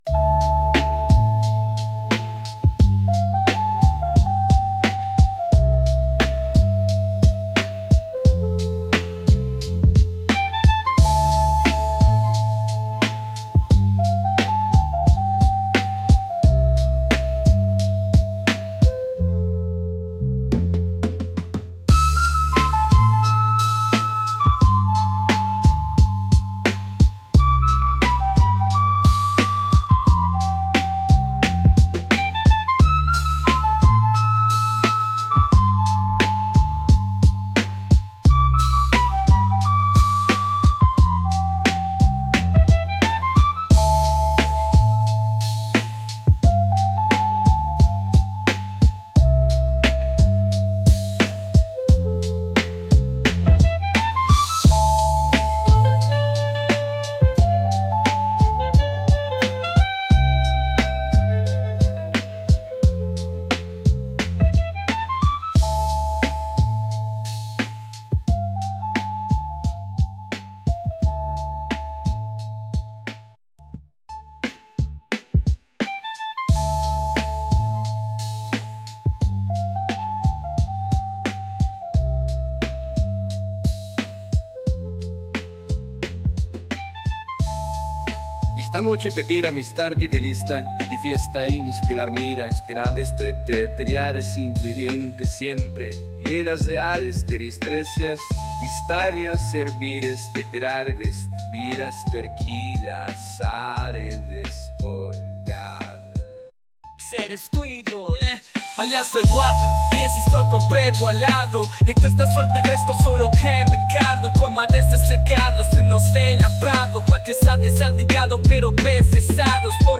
Another old beat (2020 again)
hip hop rap instrumental vintage beats sampled chill